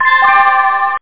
DOORBELL.mp3